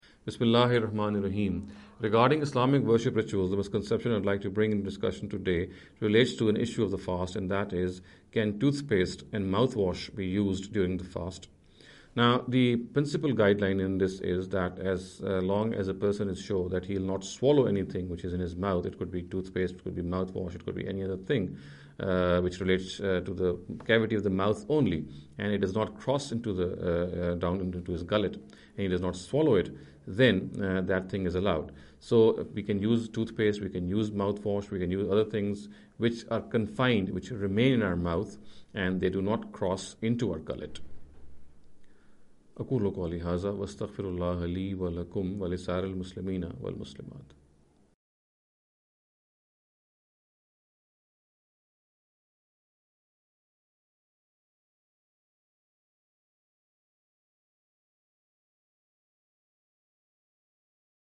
In the series of short talks “Islamic Worship Rituals